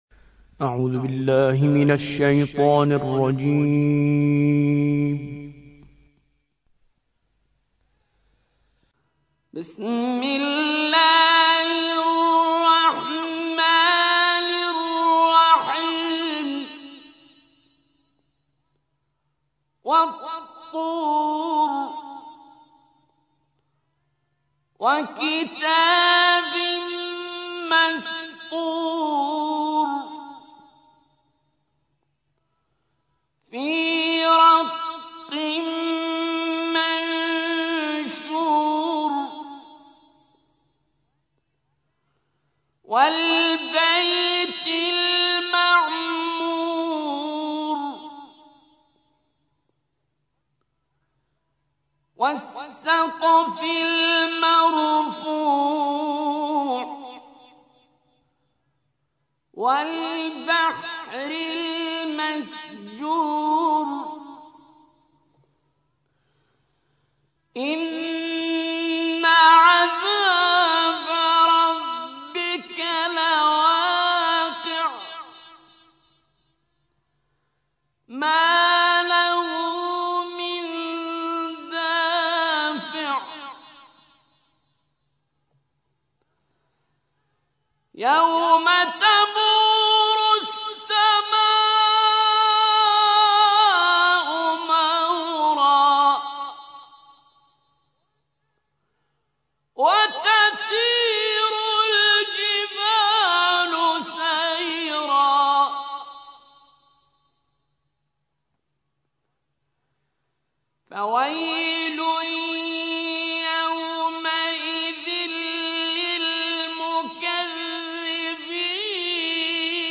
تحميل : 52. سورة الطور / القارئ كريم منصوري / القرآن الكريم / موقع يا حسين